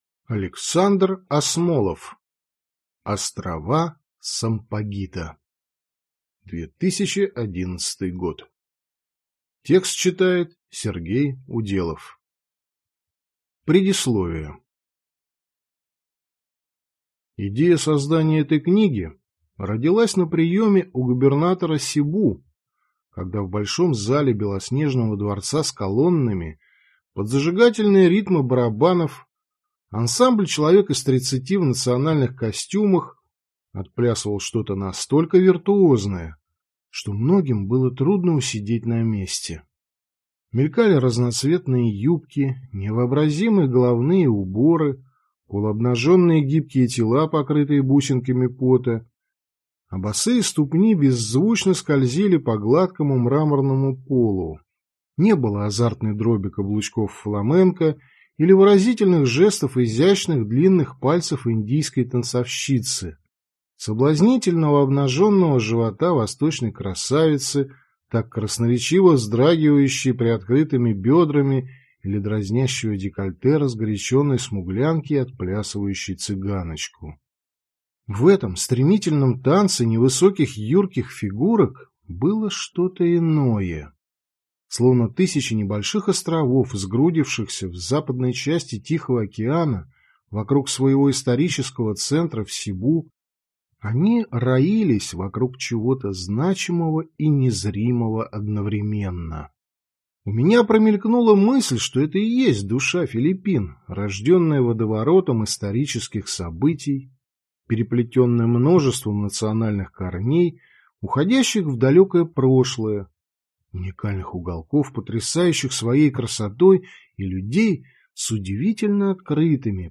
Аудиокнига Острова сампагита (сборник) | Библиотека аудиокниг